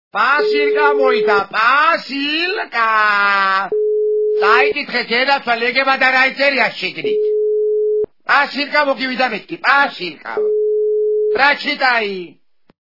При прослушивании Пасылка! - Пасылка! качество понижено и присутствуют гудки.